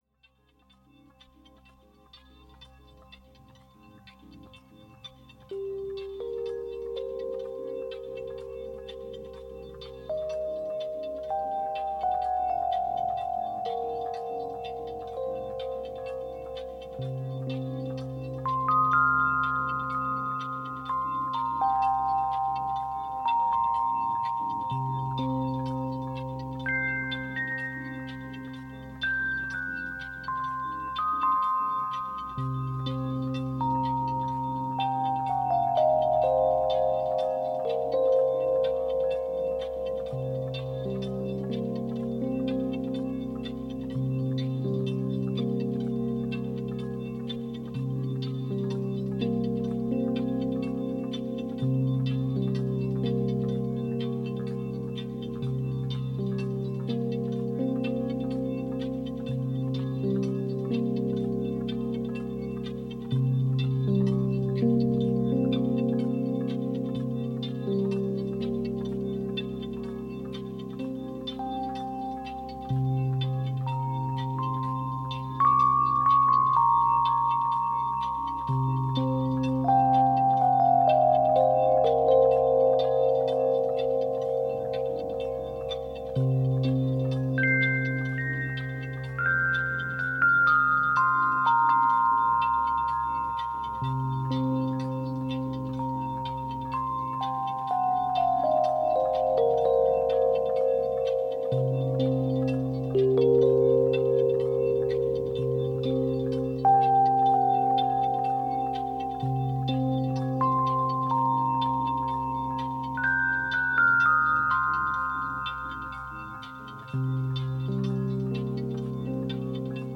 Entspannungsmusik. Tempo: 65 bpm / Datum: 04.05.2018